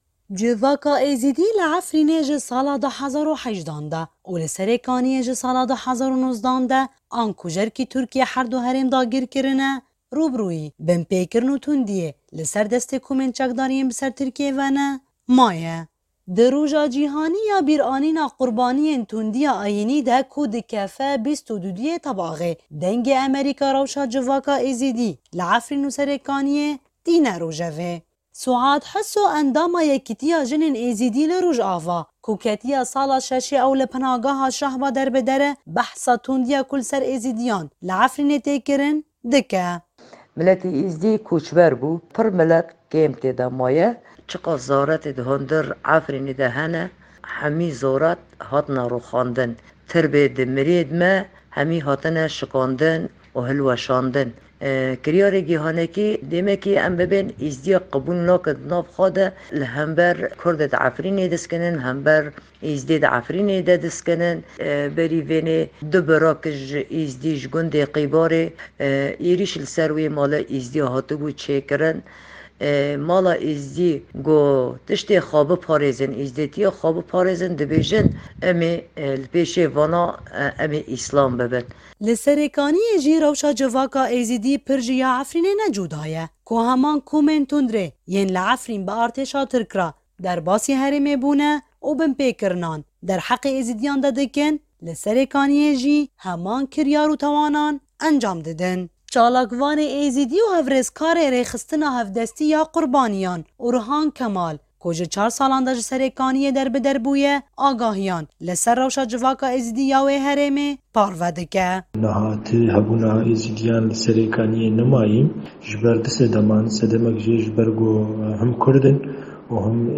Raporta Efrîn_NR_08_22_2023_Êzîdî_Rojava.mp3